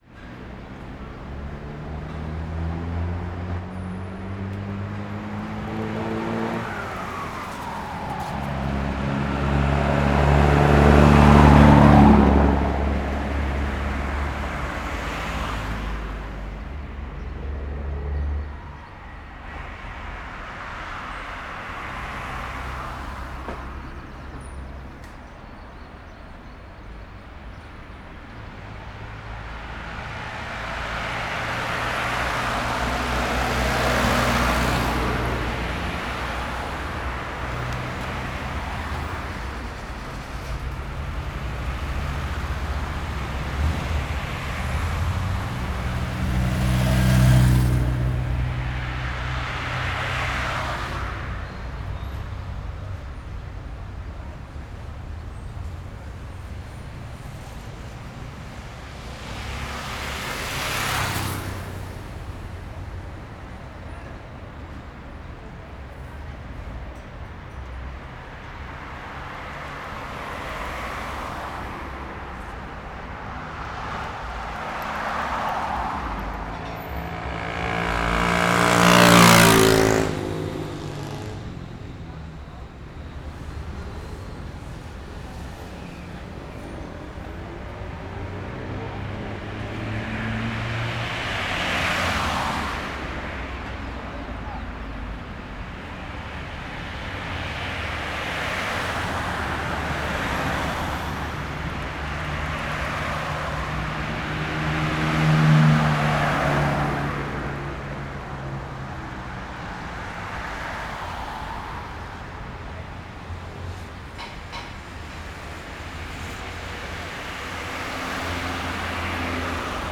Arquivo de Ambiência Urbana - Página 20 de 38 - Coleção Sonora do Cerrado
CSC-04-171-OL- Avenida caminhao passando moto e carros.wav